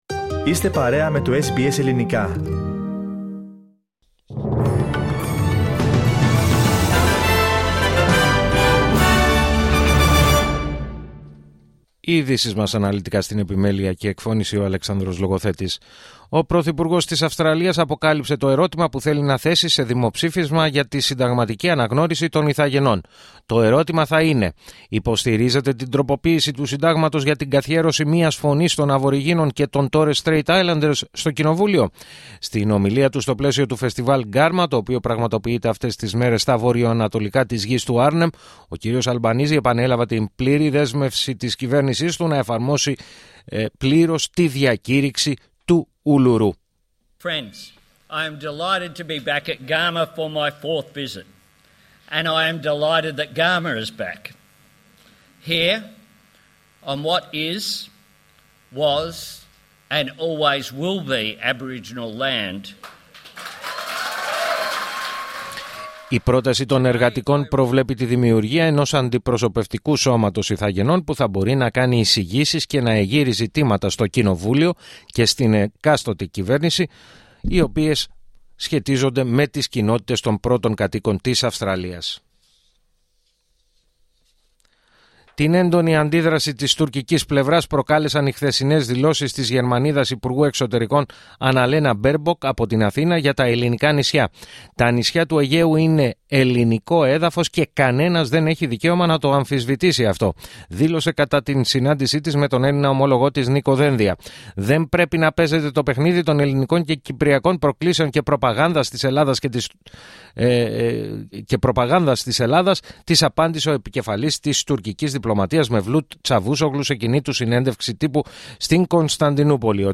Το αναλυτικό δελτίο του Ελληνικού Προγράμματος της ραδιοφωνίας SBS, στις 16:00.